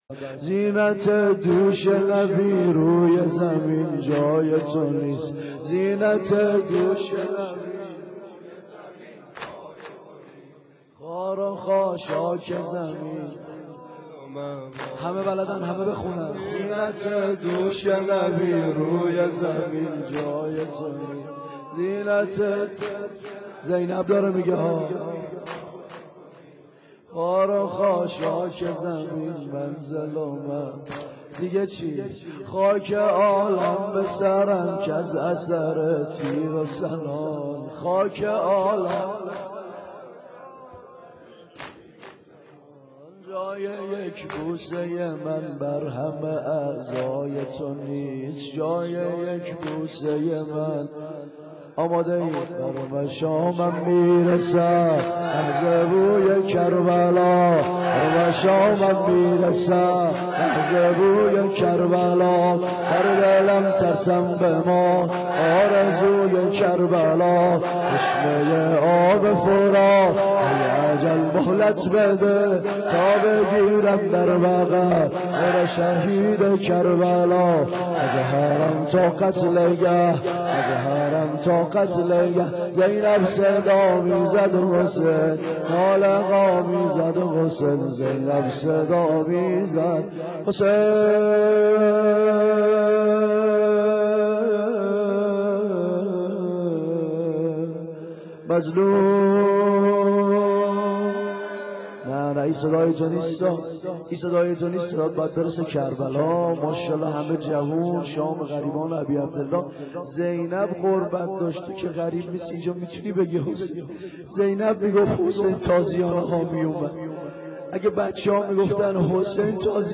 روضه کوتاه